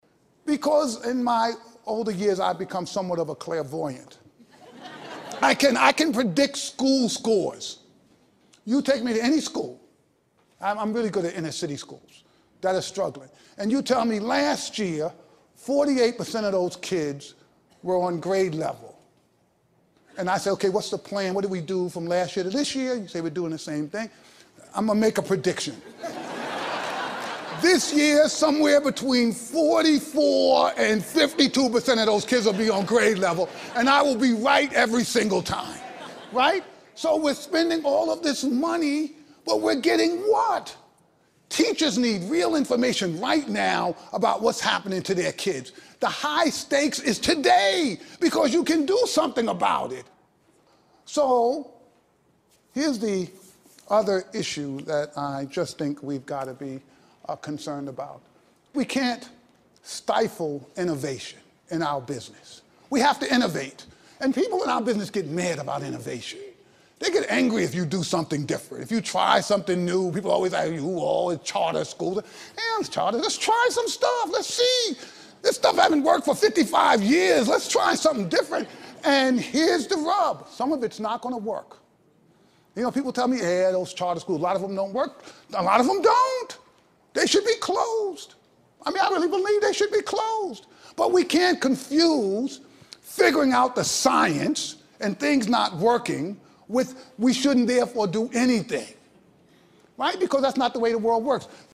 TED演讲:我们不要一成不变的教育(7) 听力文件下载—在线英语听力室